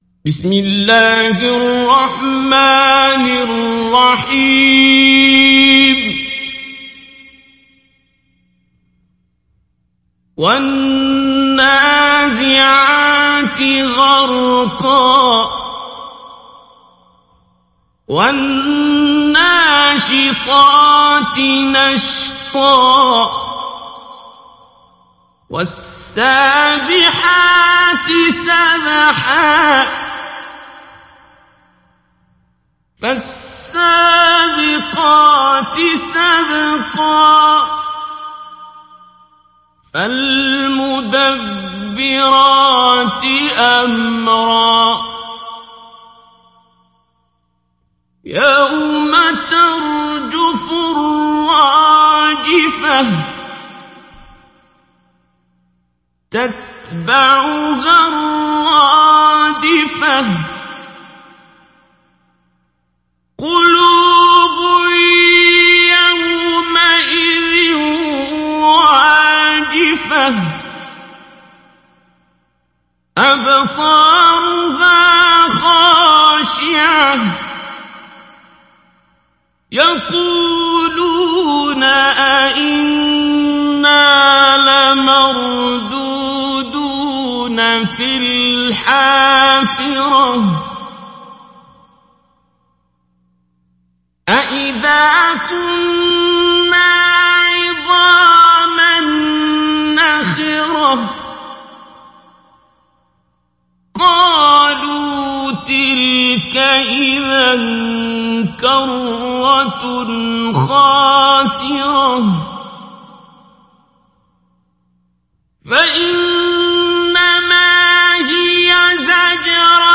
تحميل سورة النازعات mp3 بصوت عبد الباسط عبد الصمد مجود برواية حفص عن عاصم, تحميل استماع القرآن الكريم على الجوال mp3 كاملا بروابط مباشرة وسريعة
تحميل سورة النازعات عبد الباسط عبد الصمد مجود